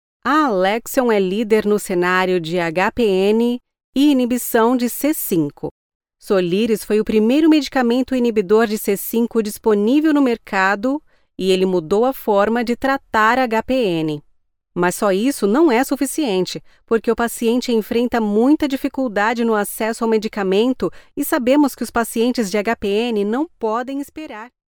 Medizinische Erzählung
Sie hat einen authentischen und freundlichen Stil und ist sehr auf Exzellenz und die Zufriedenheit ihrer Kunden bedacht.
Ihre Stimme ist angenehm und vielseitig und kann Ihnen helfen, ein einzigartiges und fesselndes Hörerlebnis für Ihr Publikum zu schaffen.
Ich habe ein professionelles Heimstudio mit allem Drum und Dran.
Acoustic Cabin, Reaper, Sound Forge, SSL 2 Interface, Mic ARC STD-3, Sony MDR-7506 Headphones